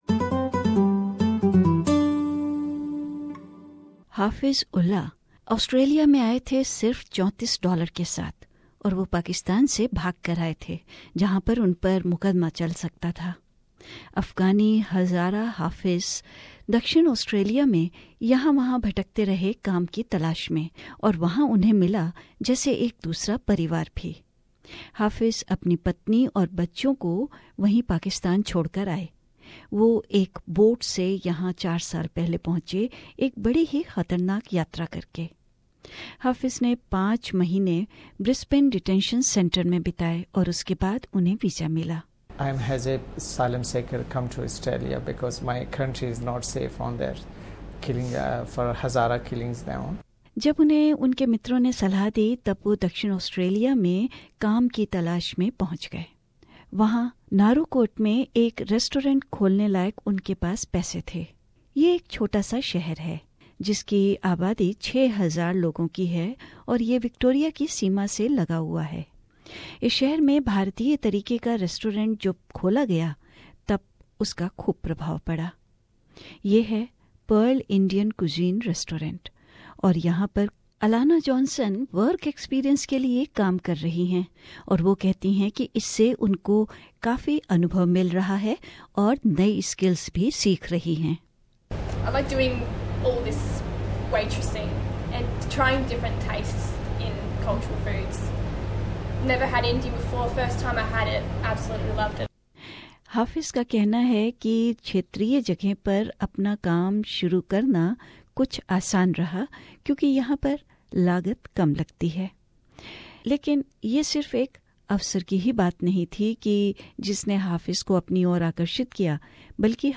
A feature